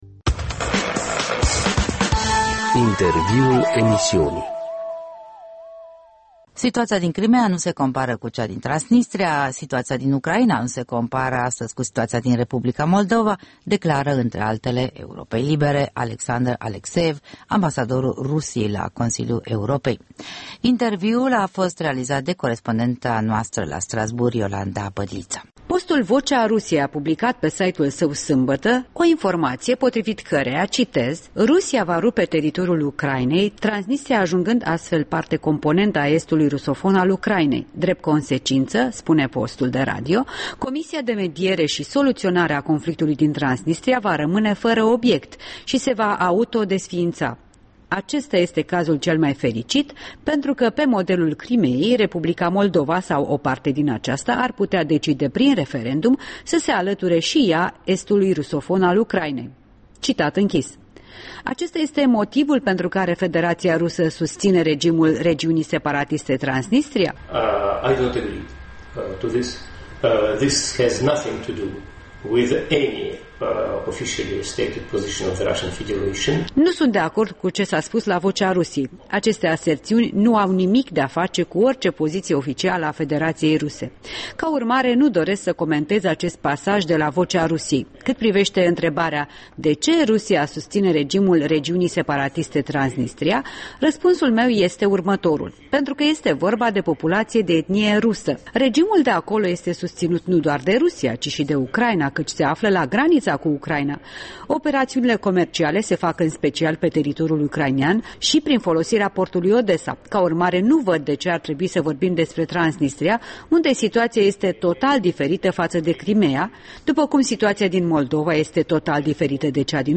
Interviul acordat Europei Libere de diplomatul Alexandr Alekseev